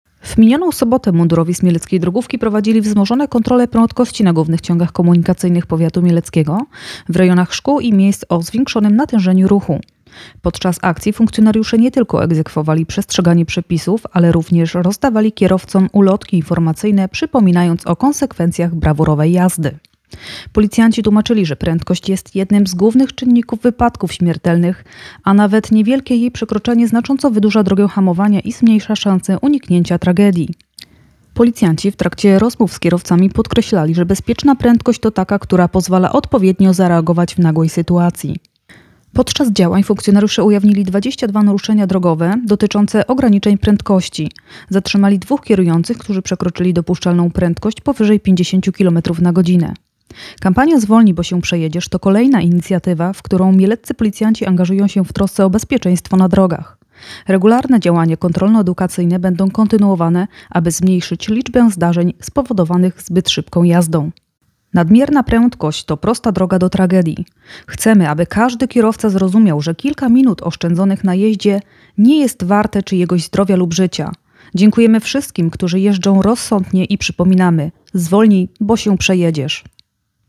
Nagranie głosowe Zwolnij bo się przejedziesz